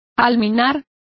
Complete with pronunciation of the translation of minarets.